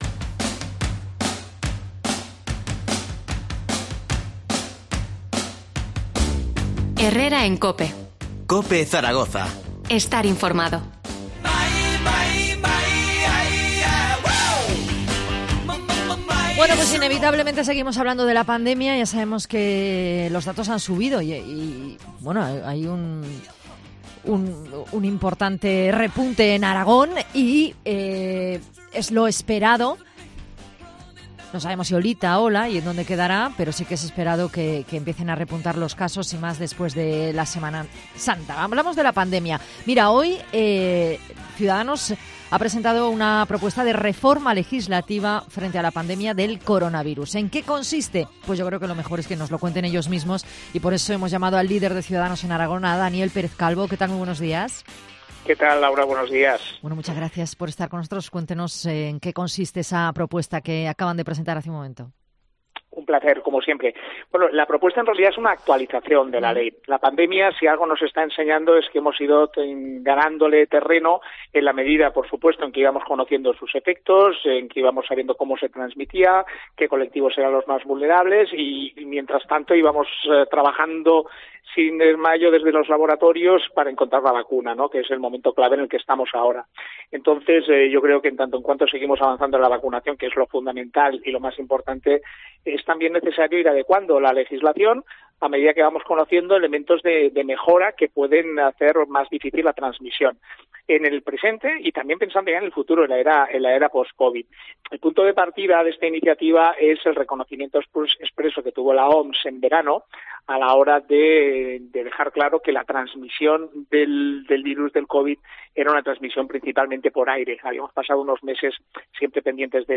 Entrevista a Daniel Pérez Calvo, líder de Ciudadanos en Aragón. 06-04-21